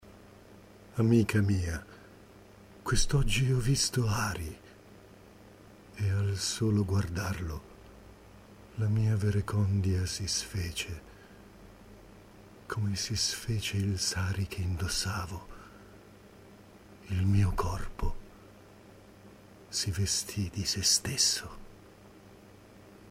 A partire dal 2006 ho partecipato ad alcuni incontri di lettura di poesie al Centro Coscienza di Milano, in Corso di Porta Nuova, 16.